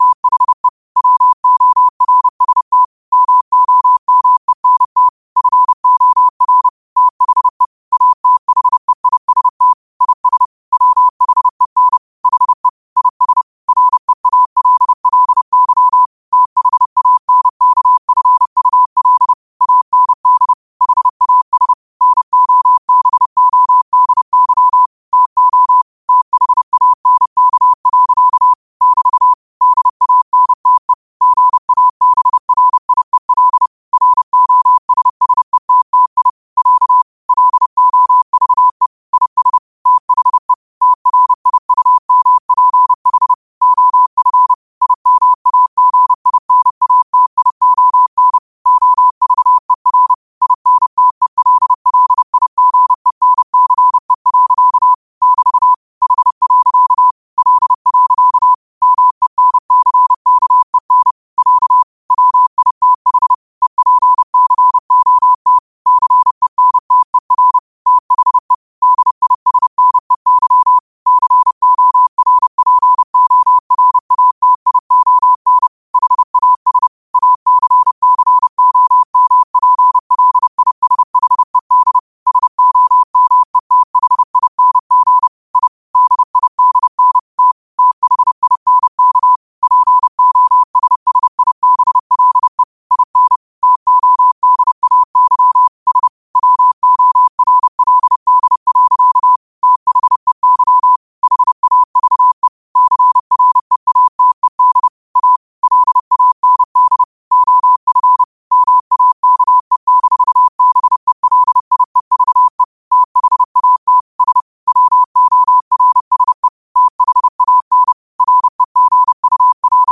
Quotes for Fri, 15 Aug 2025 in Morse Code at 30 words per minute.